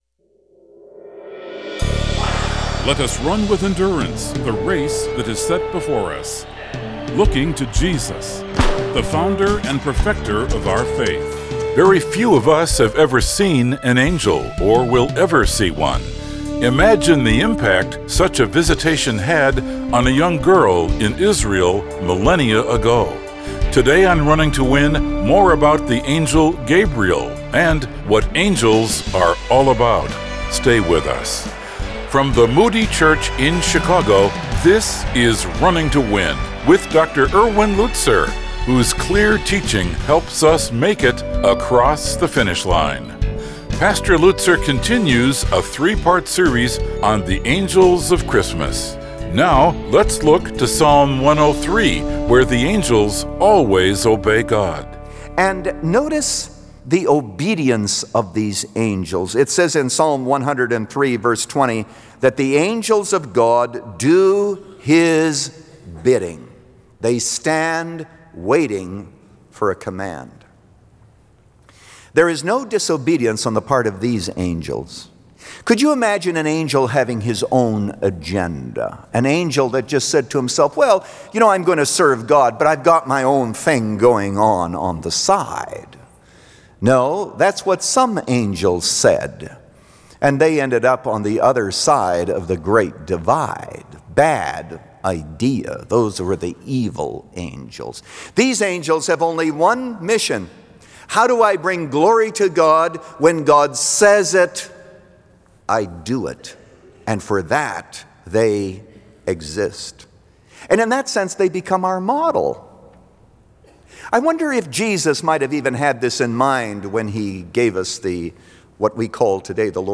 The Angel Gabriel – Part 2 of 3 | Radio Programs | Running to Win - 15 Minutes | Moody Church Media